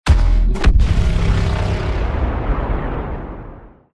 без слов
взрыв